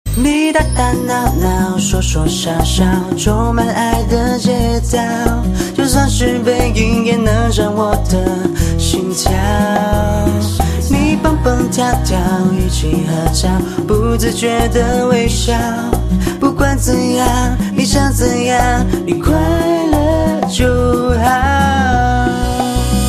M4R铃声, MP3铃声, 华语歌曲 80 首发日期：2018-05-15 00:20 星期二